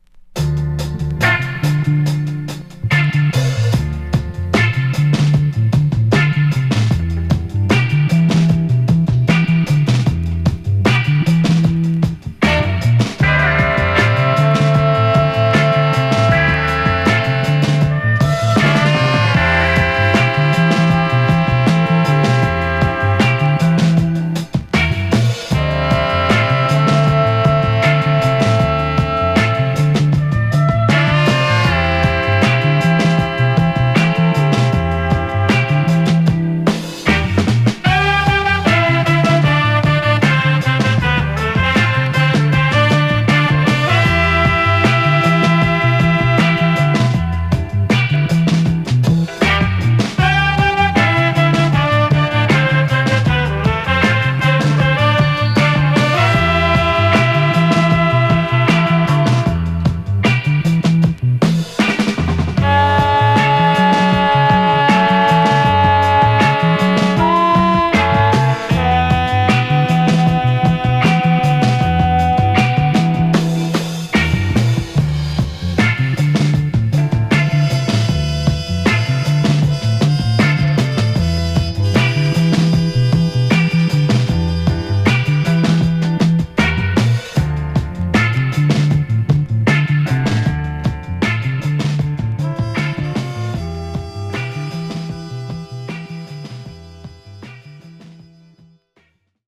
インスト・ファンク傑作です!!